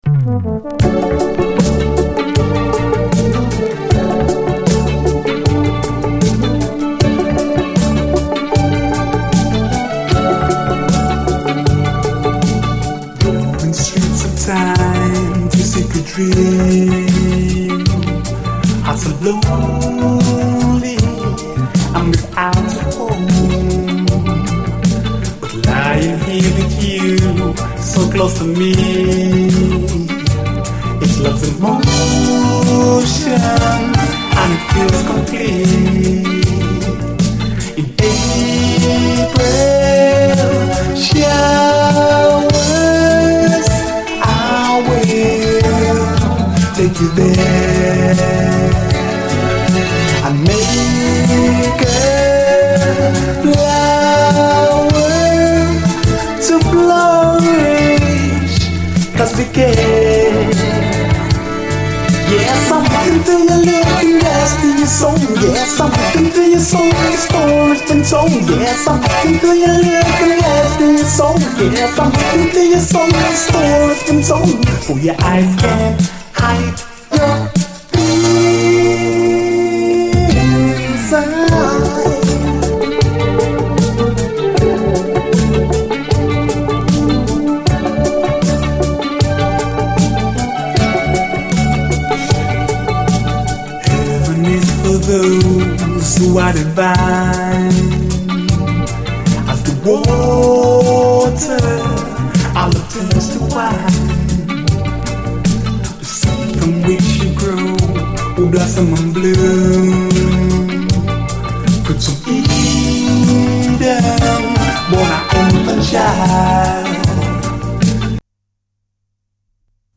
REGGAE
ニューウェーヴとディスコとUKルーツ・レゲエが融合！
ニューウェーヴとディスコとUKルーツ・レゲエが融合したサウンドが魅力です。